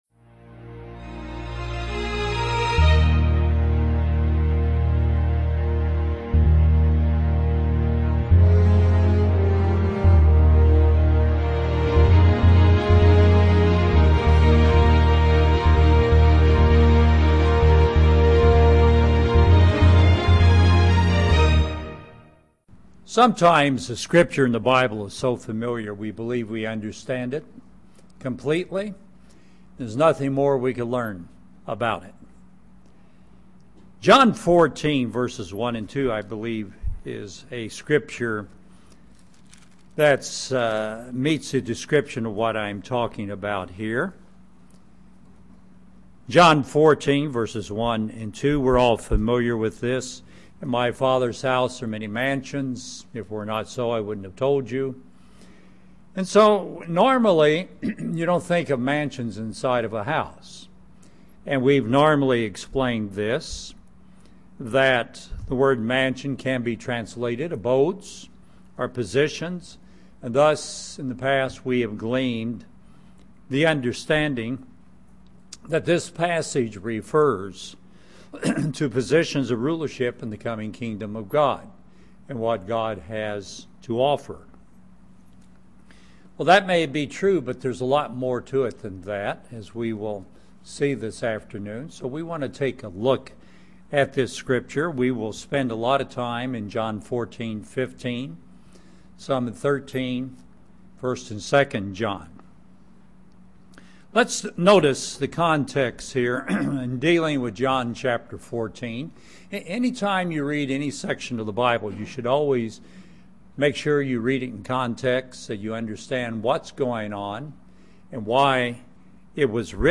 Christ's never changing promises UCG Sermon Transcript This transcript was generated by AI and may contain errors.